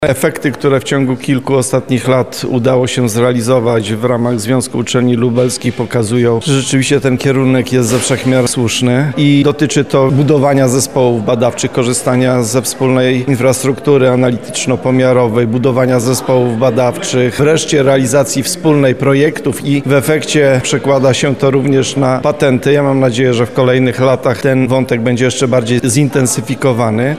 Nie walczymy o studenta, nie walczymy o projekty badawcze, a staramy się działać wspólnie tam, gdzie jest to możliwe – wyjaśnia prof. dr hab. Radosław Dobrowolski, Rektor UMCS: